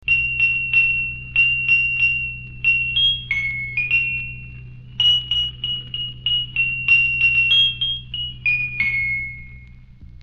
bells.mp3